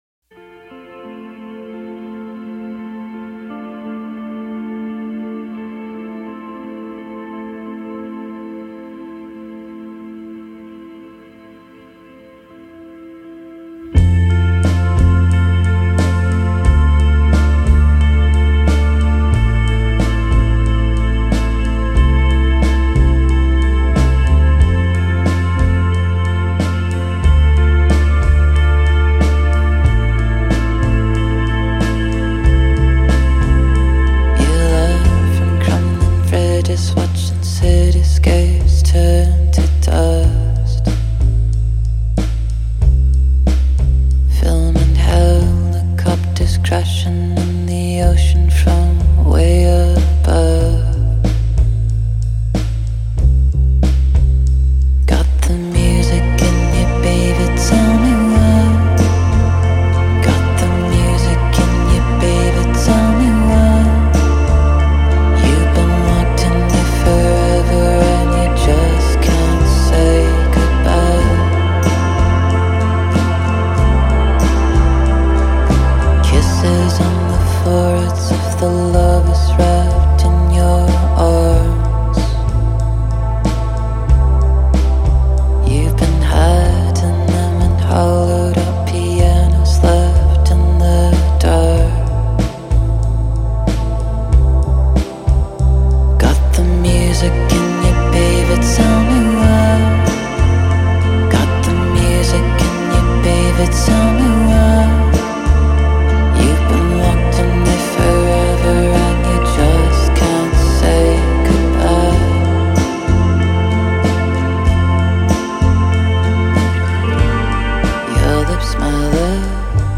سبک (الکترونیک) وکال امبینت